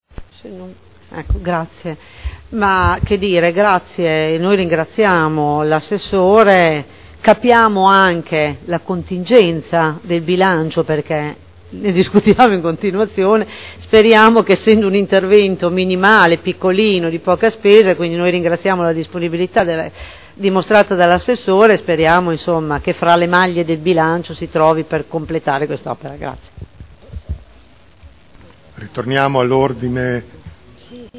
Cinzia Cornia — Sito Audio Consiglio Comunale